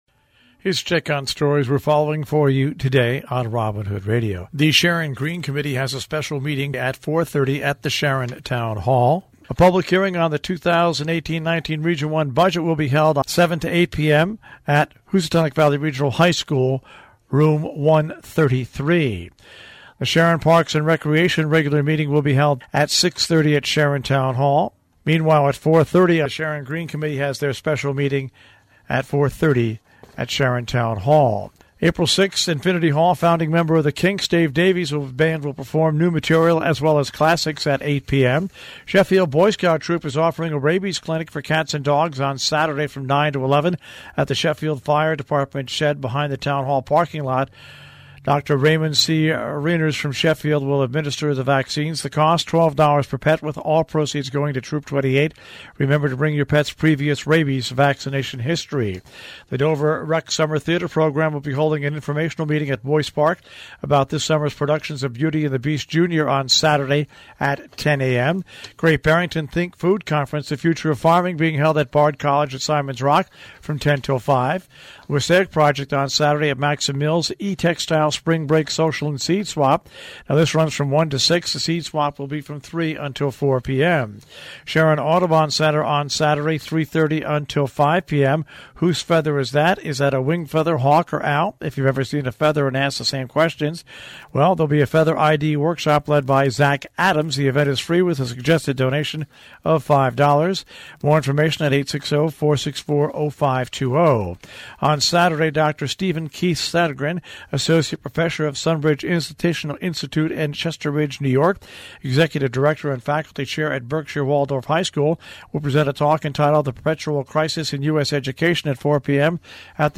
WHDD Breakfast Club News